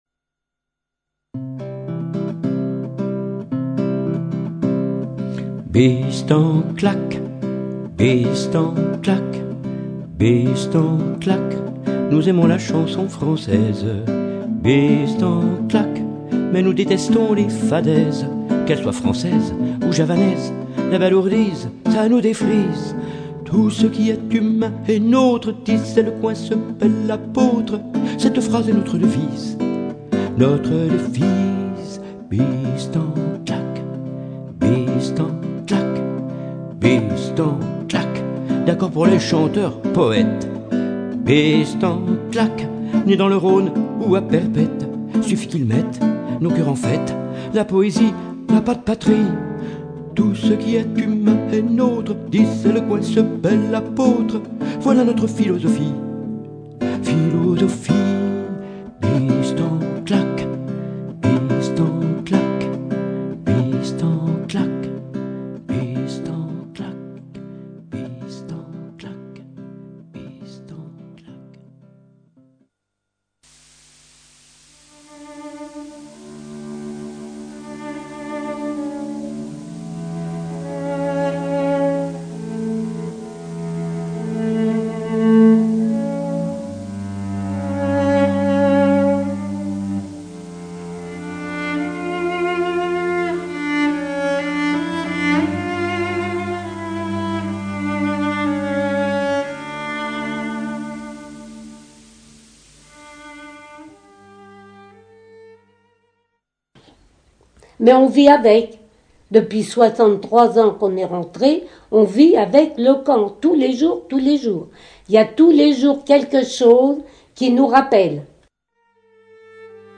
réalisées à partir d un entretien